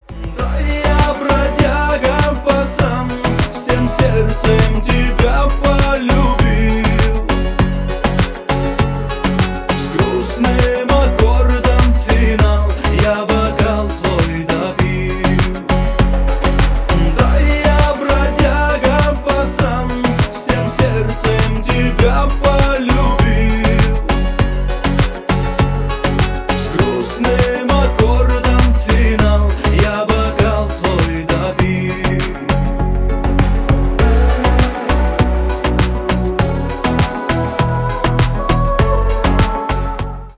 Шансон
тихие